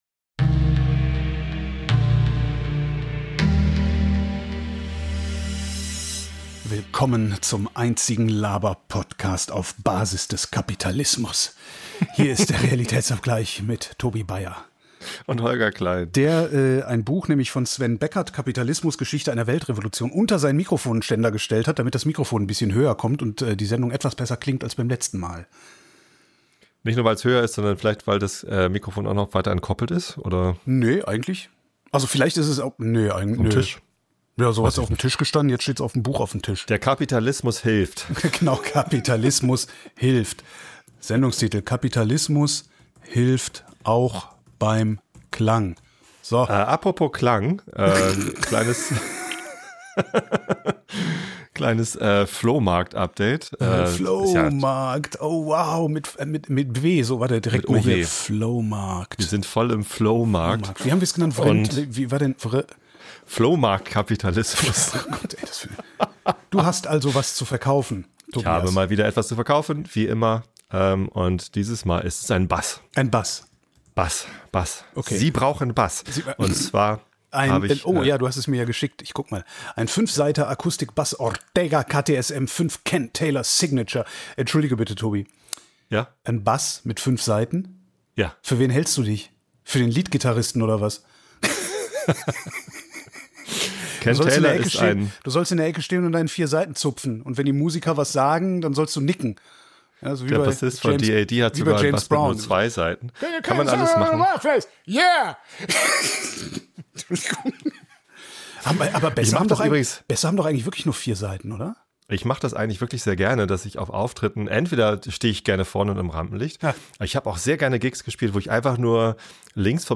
Der Laberpodcast.